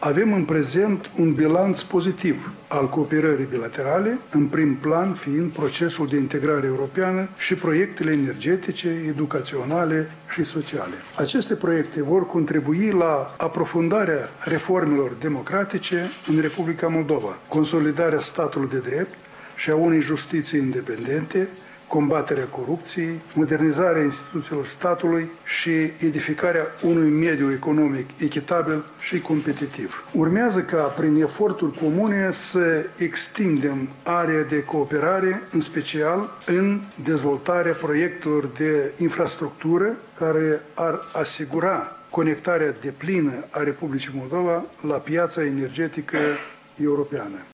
La rândul său, preşedintele Nicolae Timofti a apreciat că dialogul politic cu România este în continuare consistent şi constructiv şi a explicat că discuţiile cu omologul său român au vizat atât problemele de securitate regională, cât şi numeroasele proiecte comune, aflate în diferite faze de aplicare: